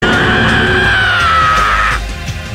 Play Kazuichi Souda SCREAMS - SoundBoardGuy
Play, download and share kazuichi souda SCREAMS original sound button!!!!
kazuichi-souda-screams.mp3